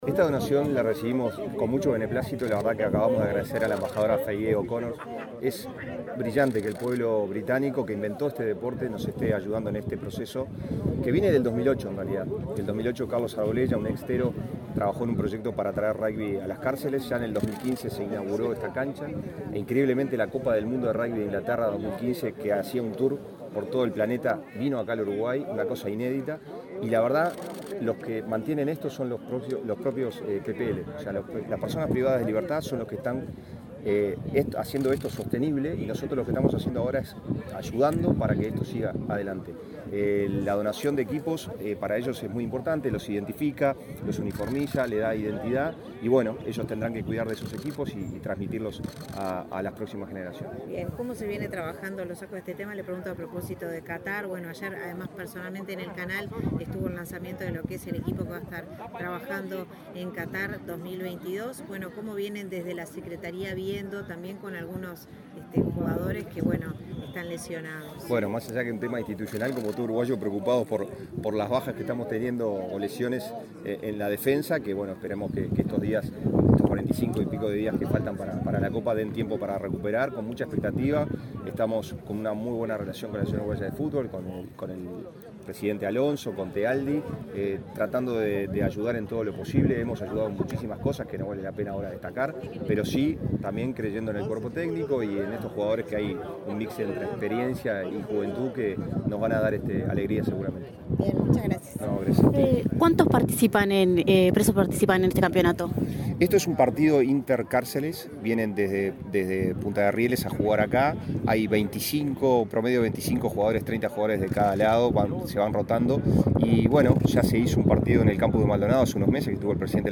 Declaraciones del subsecretario del Deporte, Pablo Ferrari
Declaraciones del subsecretario del Deporte, Pablo Ferrari 05/10/2022 Compartir Facebook X Copiar enlace WhatsApp LinkedIn Este miércoles 5 en la Unidad N.° 4 del Instituto Nacional de Rehabilitación, en Santiago Vázquez, la Secretaría Nacional del Deporte recibió una donación de la Embajada Británica, de material para la práctica de rugby. El subsecretario del Deporte, Pablo Ferrari, señaló la importancia de la donación.